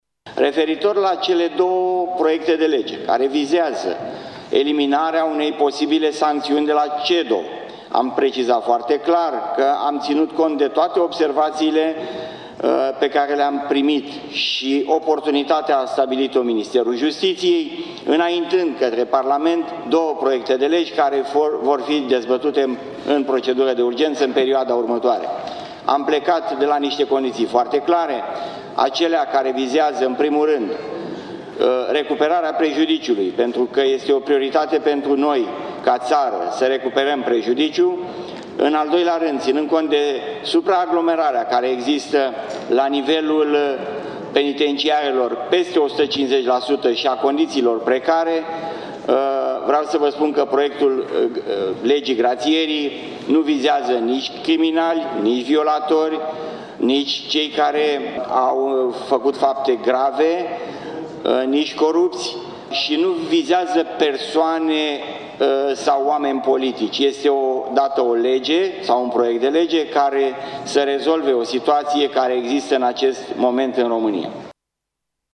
Ministrul justiției, Florin Iordache, a susținut o declarație de presă în care a dorit să facă unele precizări privind ordonanțele de urgență adoptate azi-noapte și care au adus modificări la codurile penale.